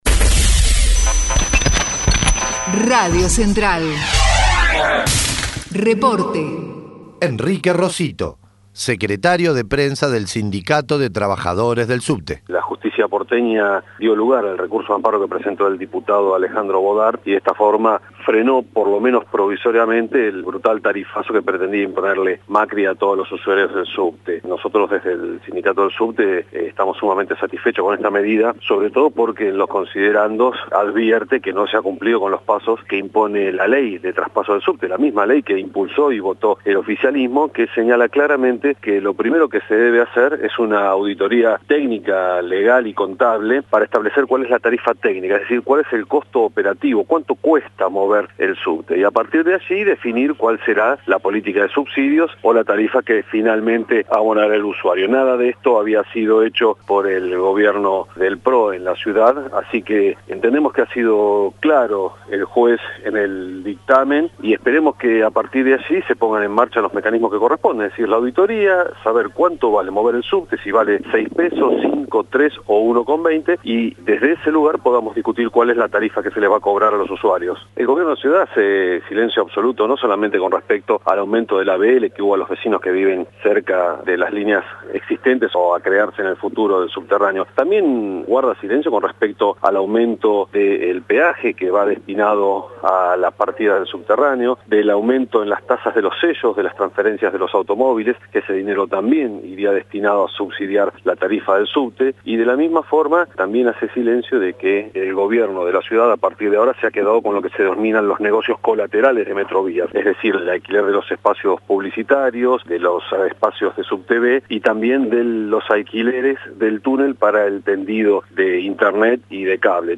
declaraciones a Radio Central